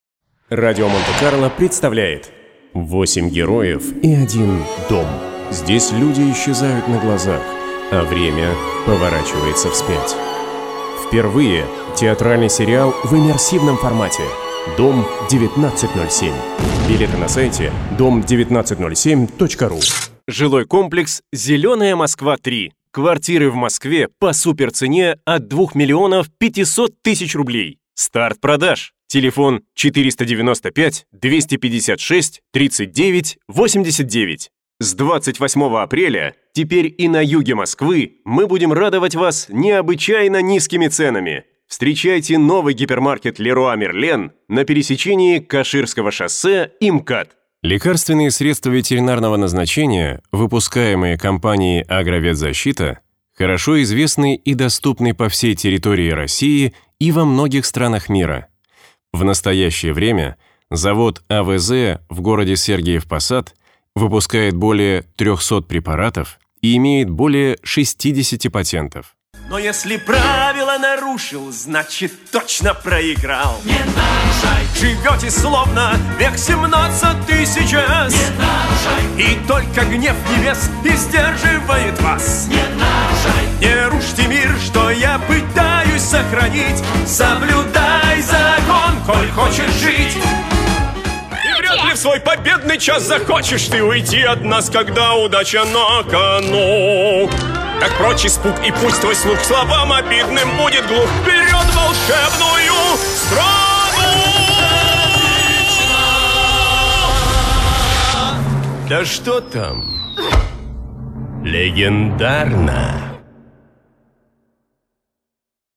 Мужской
Певческий голос
Баритон